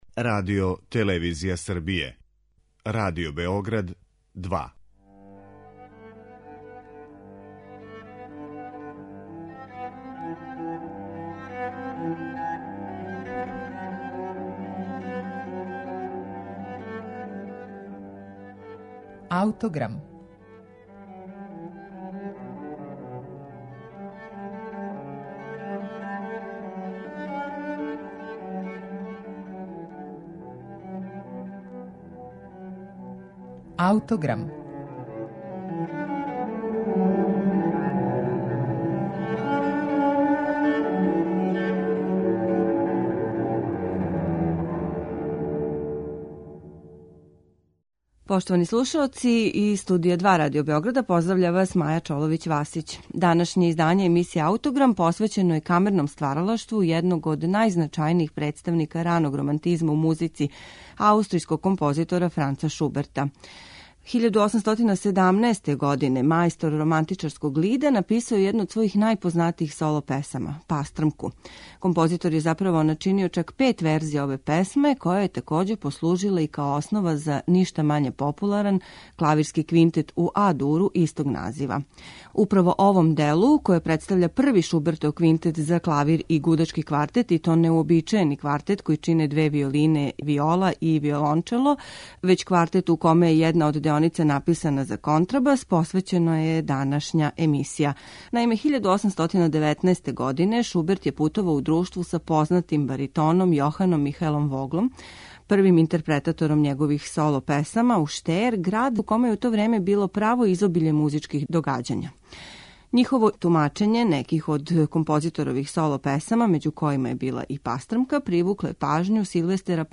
Данашња емисија је посвећена једном од најпознатијих остварења Франца Шуберта - Квинтету за клавир и гудачки квартет у А-дуру.